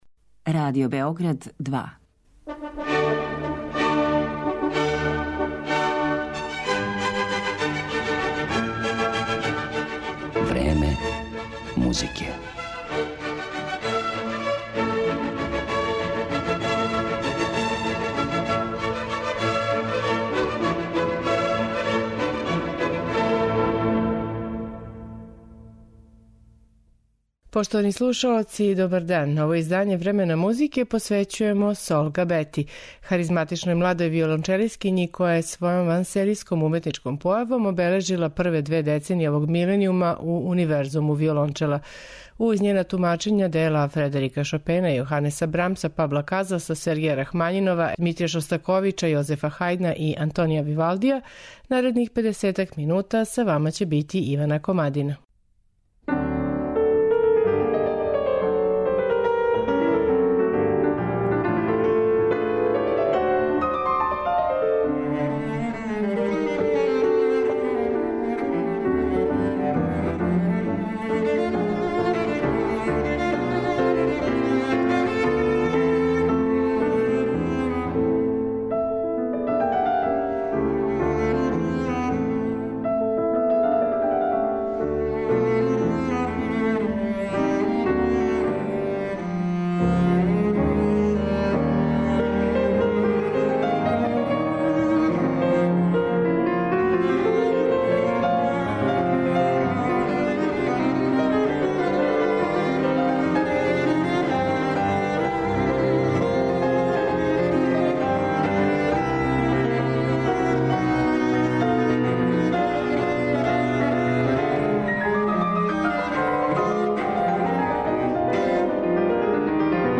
Музички портрет виолончелисткиње Сол Габете